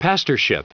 Prononciation du mot : pastorship